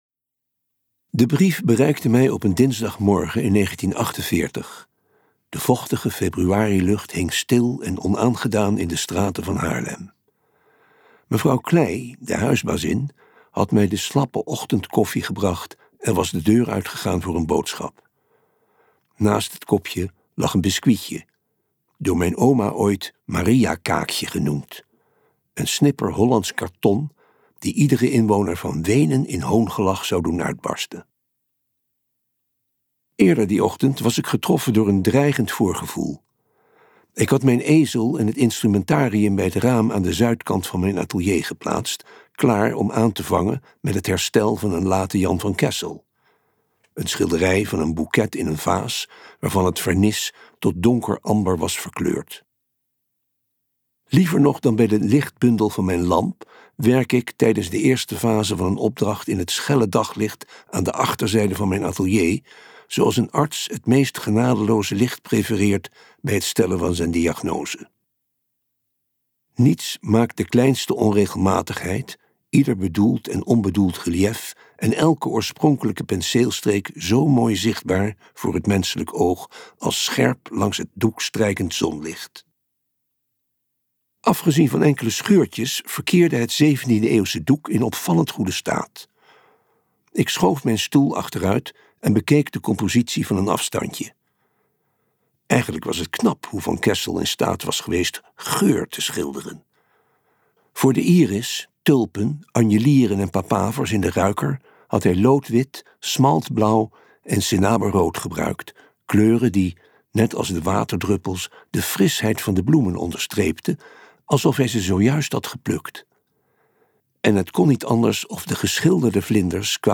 Ambo|Anthos uitgevers - Narcis luisterboek